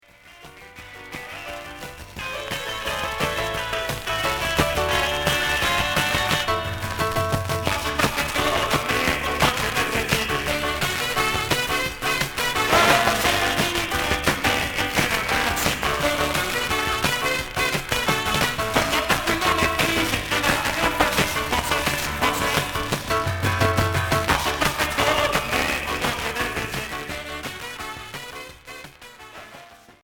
Rock Cinquième 45t retour à l'accueil